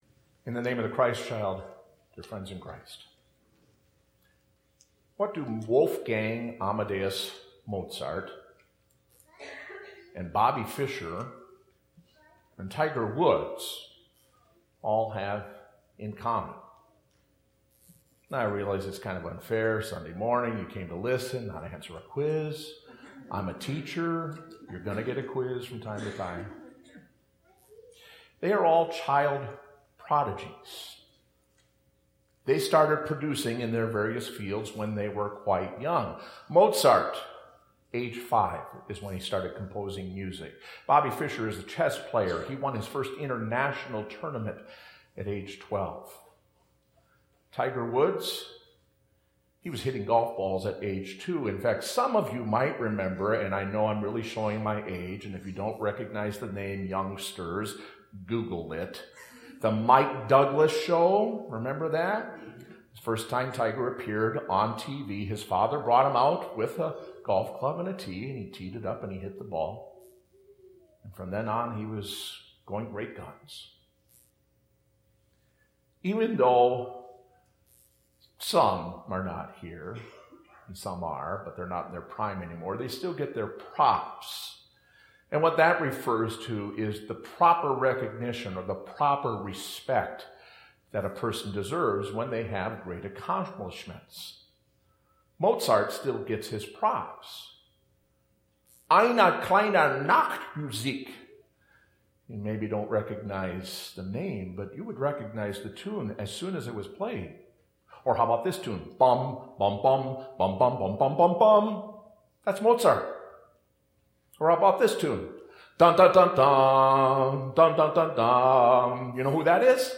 Services (the most recent service is in the first box)